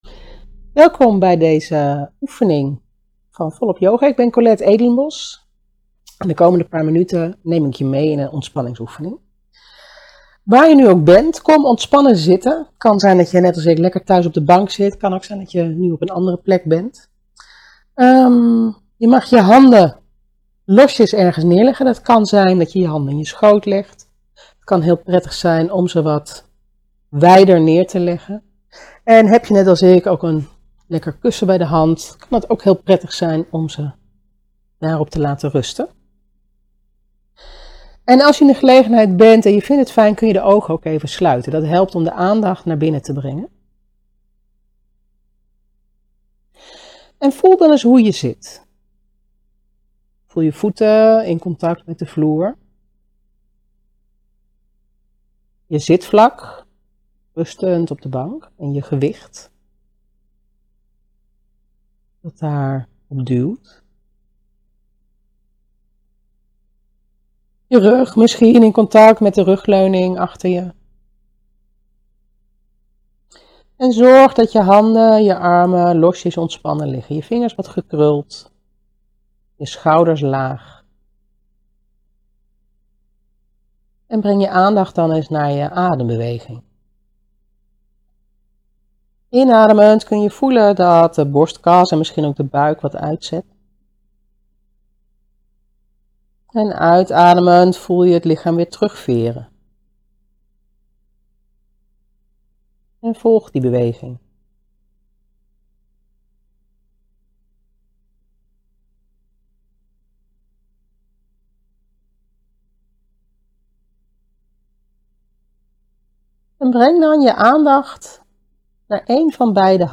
met Volop Yoga - luisteroefening hoort bij de gratiscursus